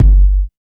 80 KICK 2.wav